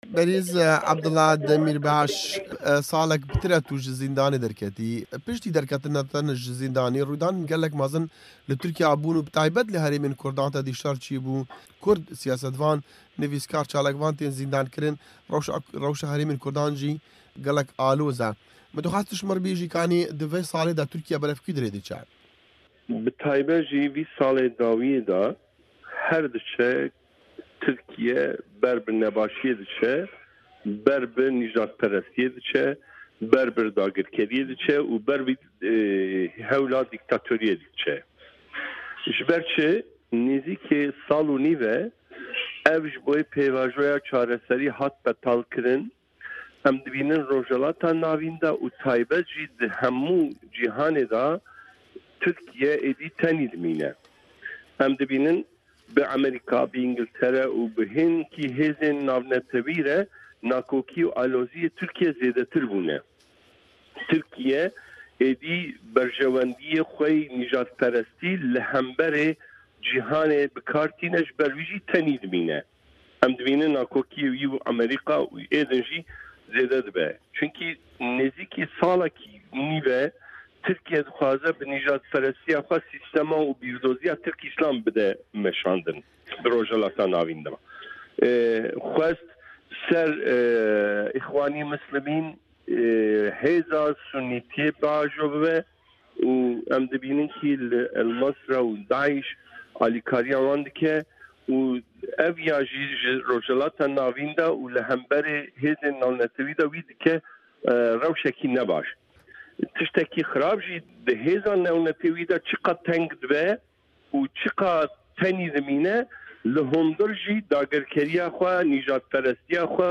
Hevpeyvîn digel Abdullah Demîrbaş